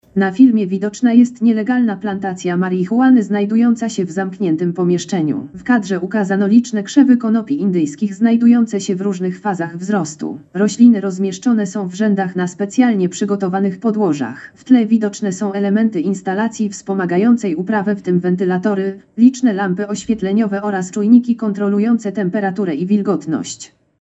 Nagranie audio Audiodeskrypcja filmu cios w narkotykowy biznes.